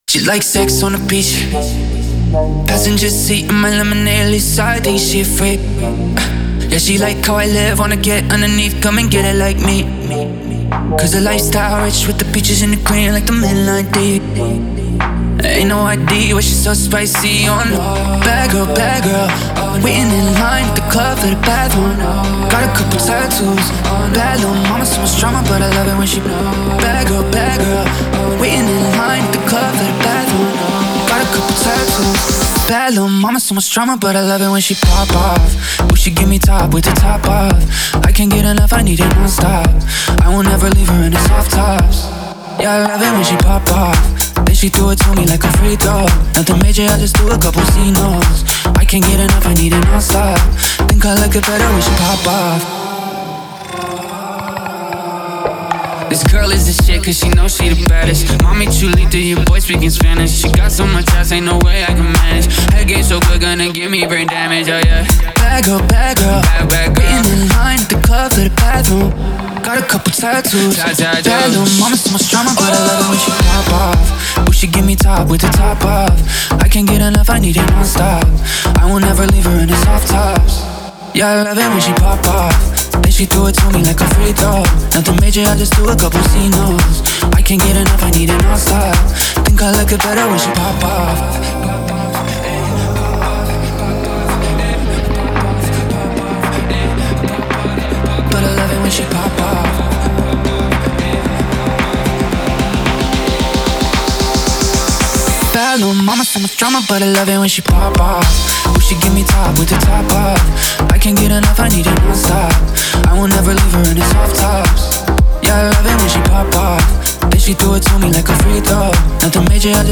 это зажигательный трек в жанре EDM